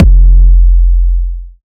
Index of /Antidote Advent/Drums - 808 Kicks
808 Kicks 05 F.wav